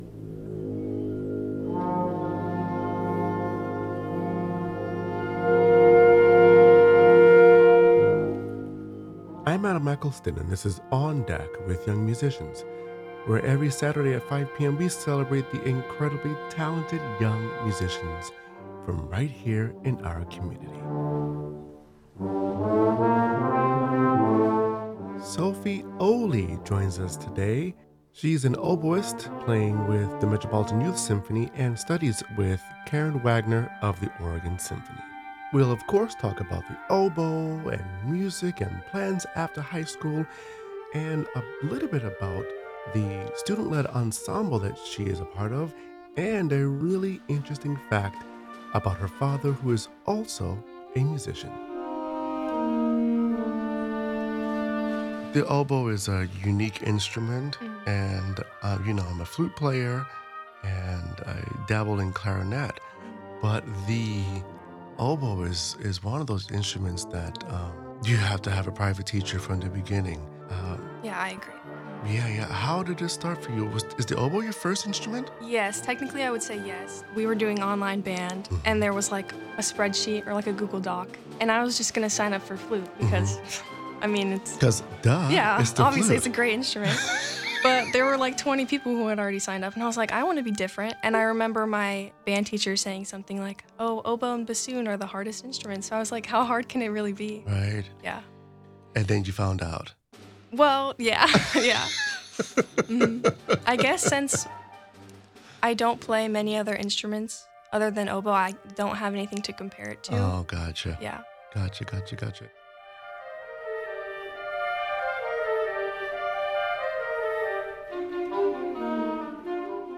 Be a guest on this podcast Language: en-us Genres: Arts , Music , Music Interviews , Performing Arts Contact email: Get it Feed URL: Get it iTunes ID: Get it Get all podcast data Listen Now...